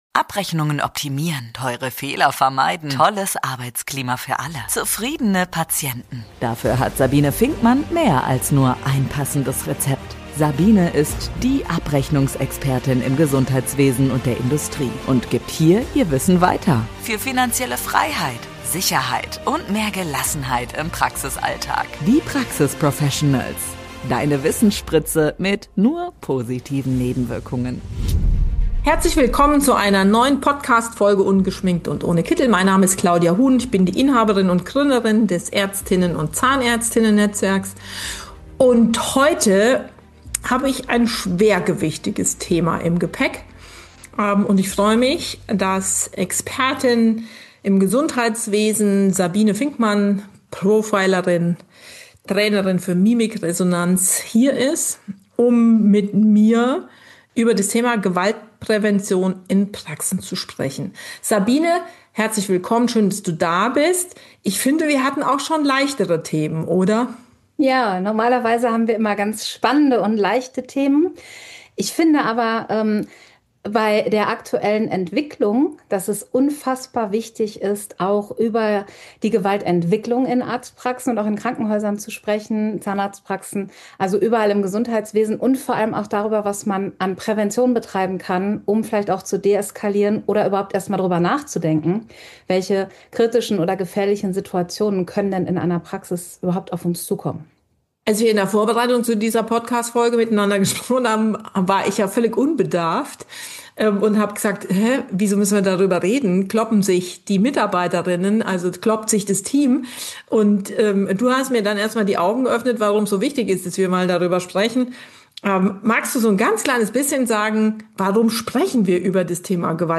Gemeinsam besprechen die beiden, wie man gefährliche Situationen erkennt, welche Rolle Kommunikation spielt und welche ganz konkreten Schutz- und Handlungsmöglichkeiten Praxen haben – inklusive Tipps zu Trainings, Notfallplänen und dem Einbezug der Polizei.